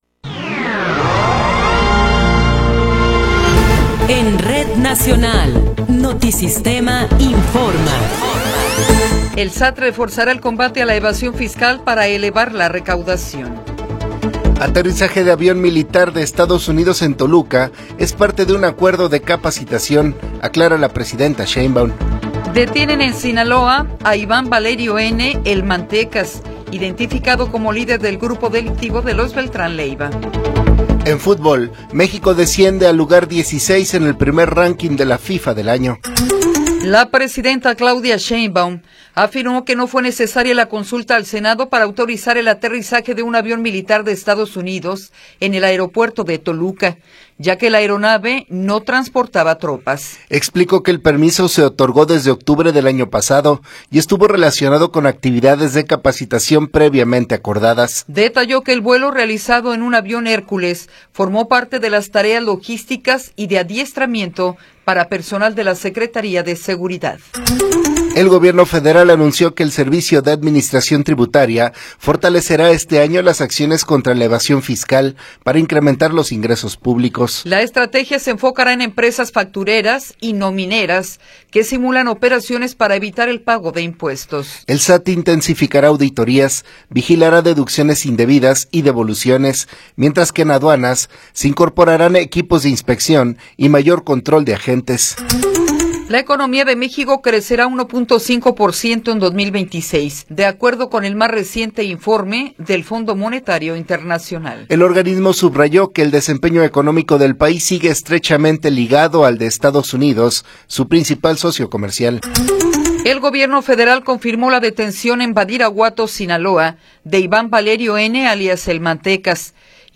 Noticiero 8 hrs. – 20 de Enero de 2026
Resumen informativo Notisistema, la mejor y más completa información cada hora en la hora.